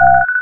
mine_seek.wav